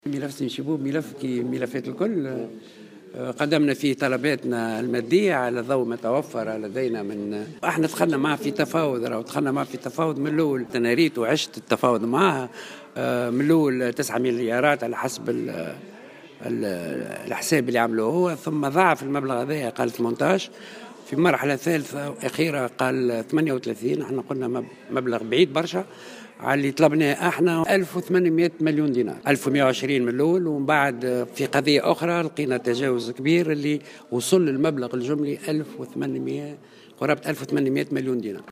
وأضاف الهمامي في تصريح لمراسل الجوهرة اف ام، أن المبلغ الذي طلبته الدولة تم تحديده على ضوء ما توفر من ملفات فساد، إلا أن شيبوب عرض في مرحلة أولى من التفاوض 9 ملايين دينار، ثم ضاعف المبلغ لـ18 مليون دينار ثم في مرحلة ثالثة وأخيرة 38 مليون دينار.